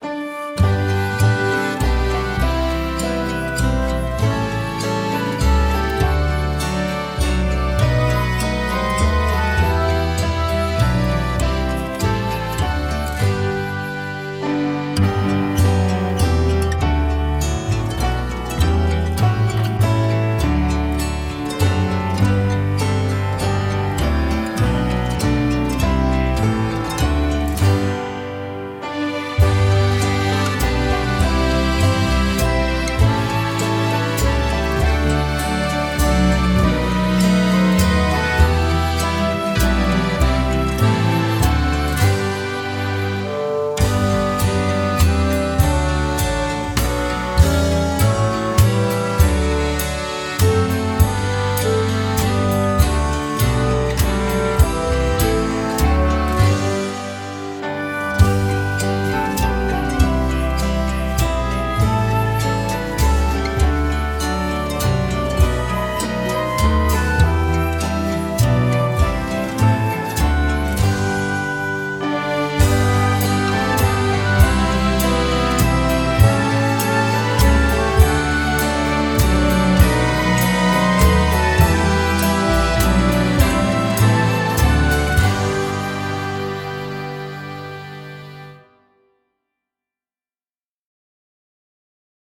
Genre: Pop-Folk.